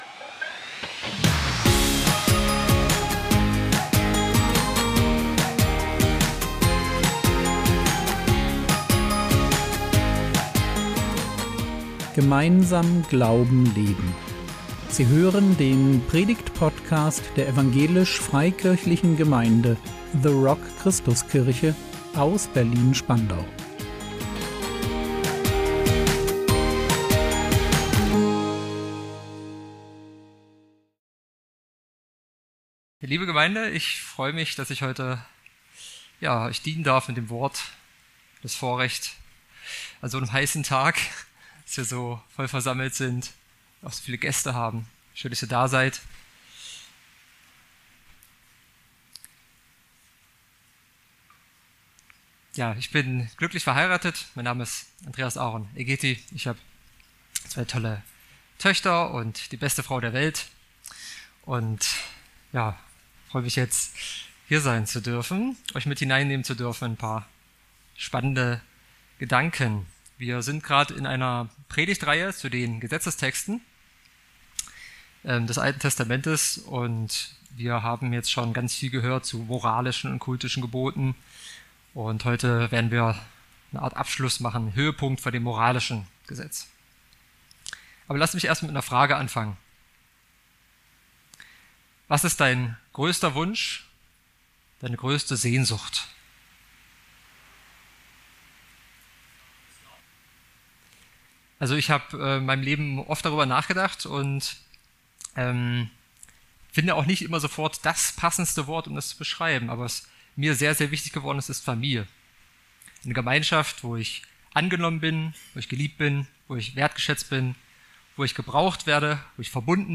Das Höchste der Gebote | 22.06.2025 ~ Predigt Podcast der EFG The Rock Christuskirche Berlin Podcast